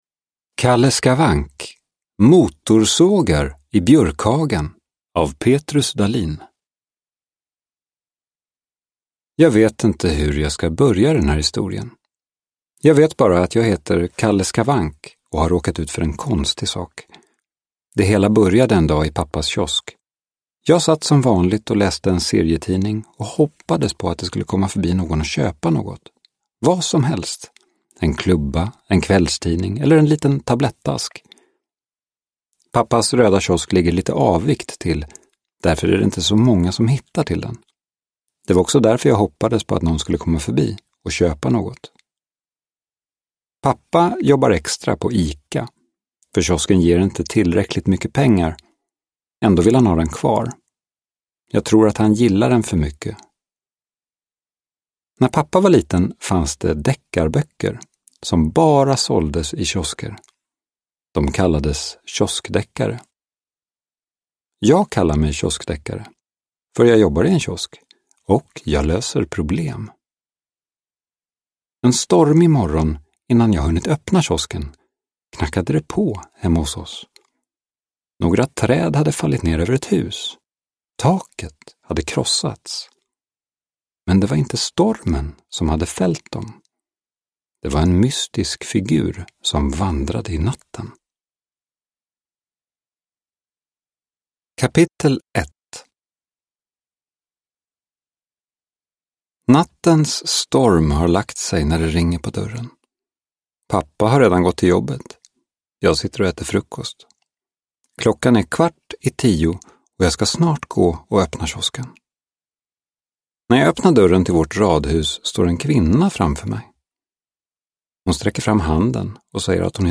Motorsågar i Björkhagen – Ljudbok – Laddas ner